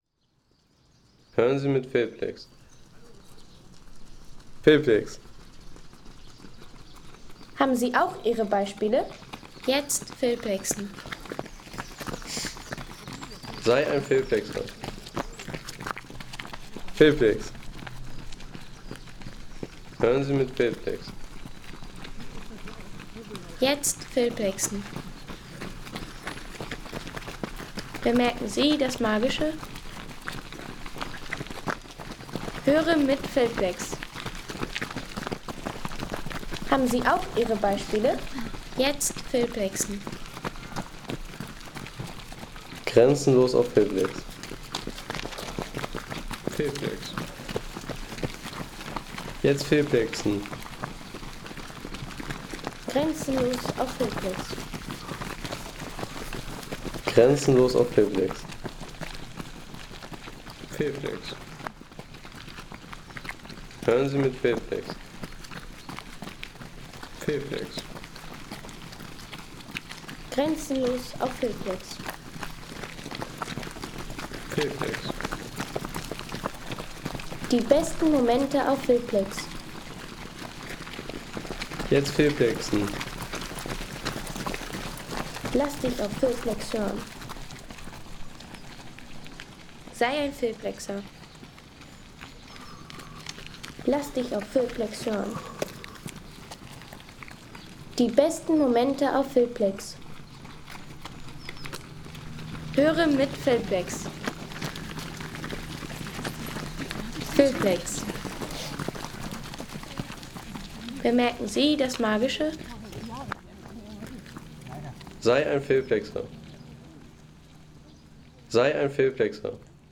Im Schritt Bleiben Home Sounds Menschen Laufen Im Schritt Bleiben Seien Sie der Erste, der dieses Produkt bewertet Artikelnummer: 172 Kategorien: Menschen - Laufen Im Schritt Bleiben Lade Sound.... Frühjahrs-Lauf im Park – Die Klänge von unzähligen Läufer-Schrit ... 3,50 € Inkl. 19% MwSt.